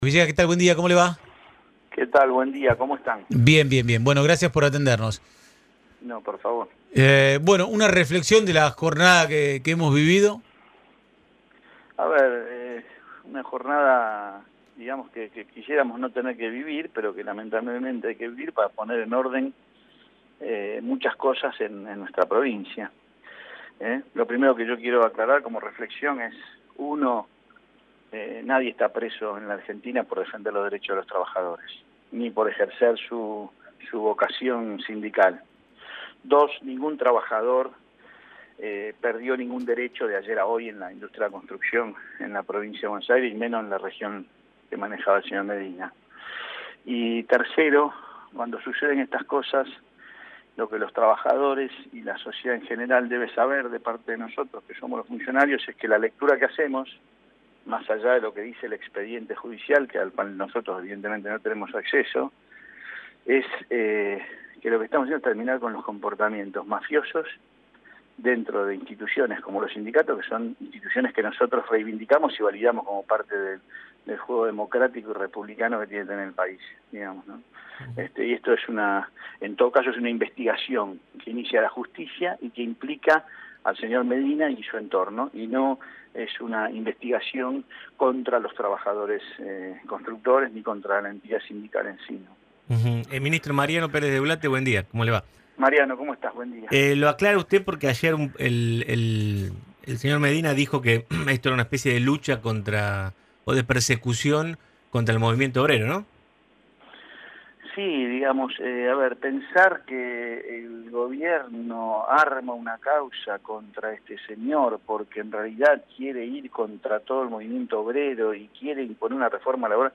Audio La Redonda: Ministro de Trabajo bonaerense, Marcelo Villegas